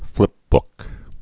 (flĭpbk)